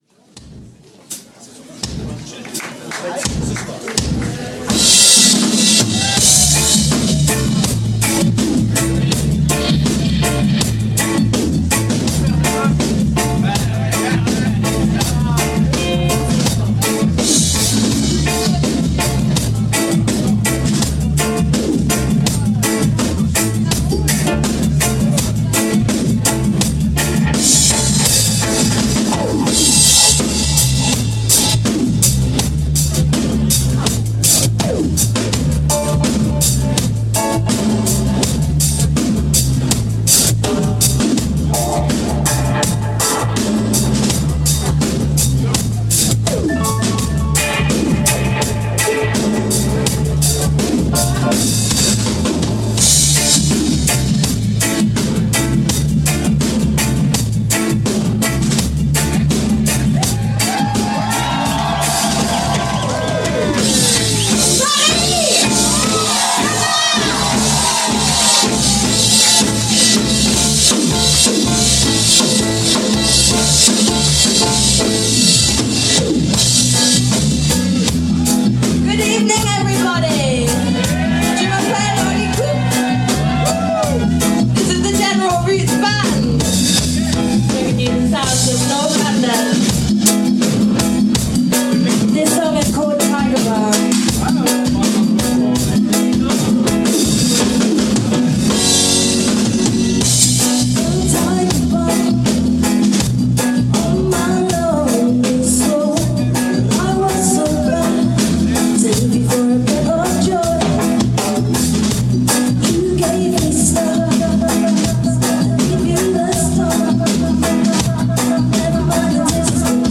Recorded live at La Maroquinerie
Referring to her sound as Tropical Pop
Reggae and Rock Steady
dreamy vocals